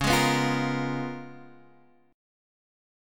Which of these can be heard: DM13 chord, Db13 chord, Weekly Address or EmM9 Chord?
Db13 chord